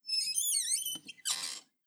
ClosetClosing1Sound.wav